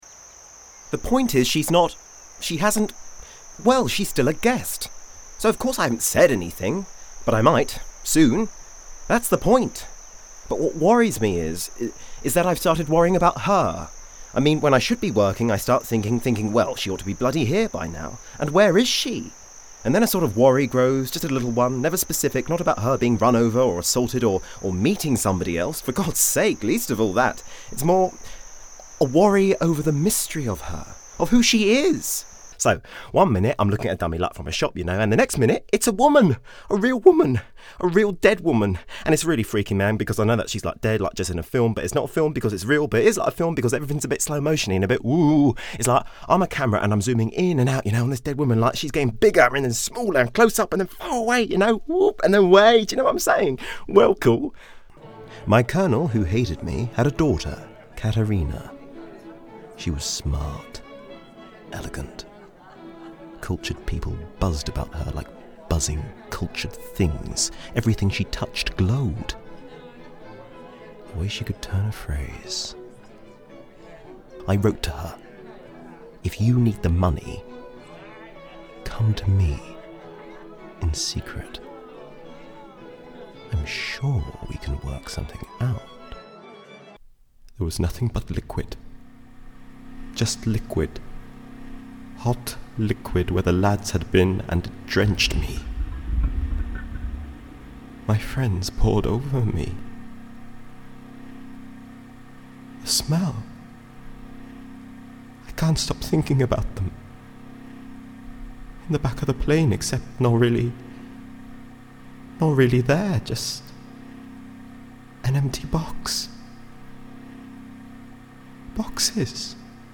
1. Audio Drama
HOME STUDIO
RP
African, American, Estuary, London, RP, Russian